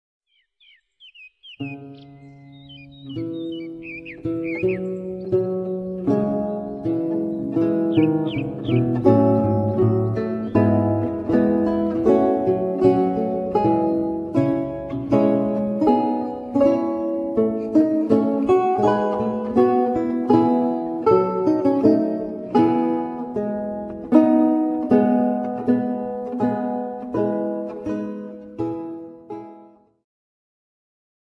ジャンル Progressive
リュート
管楽器フューチュア
弦楽器系
古楽
癒し系
中世・ルネサンス・初期バロックの曲をブロークンコンソート形式で演奏。